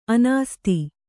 ♪ anāsti